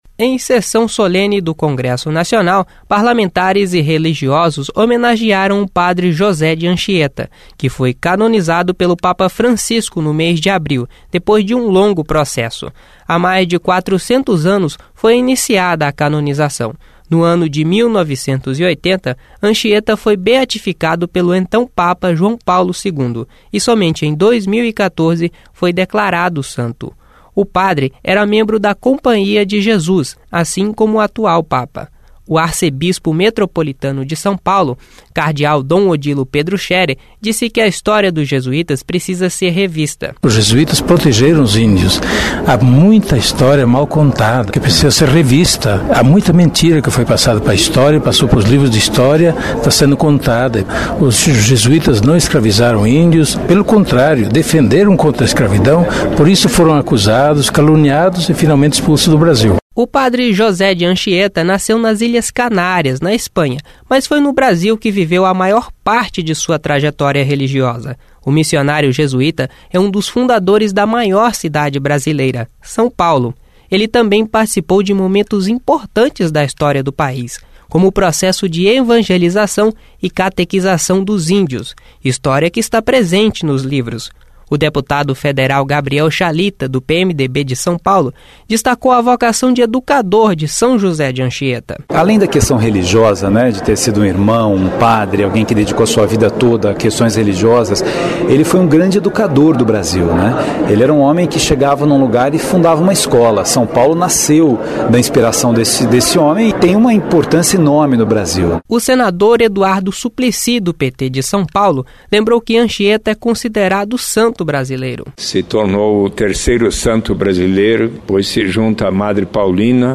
O Arcebispo Metropolitano de São Paulo, Cardeal Dom Odilo Pedro Scherer disse que a história dos jesuítas precisa ser revista.